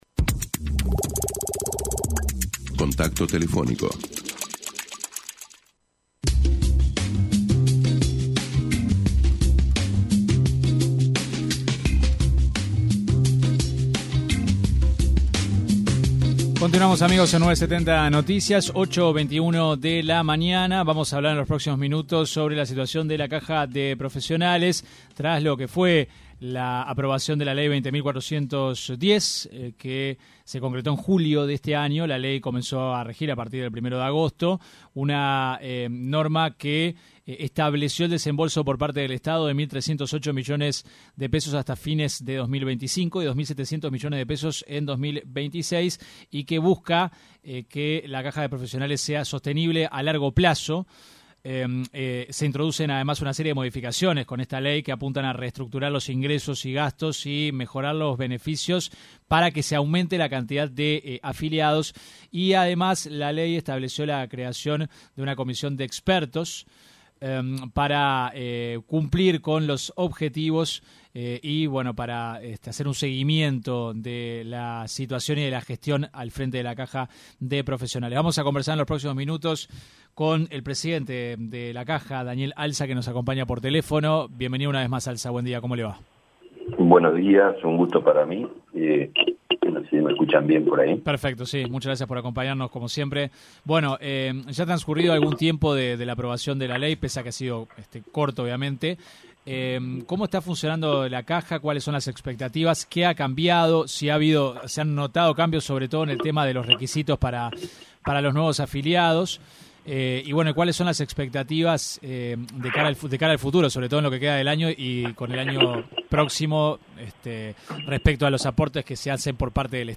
entrevista con 970 Noticias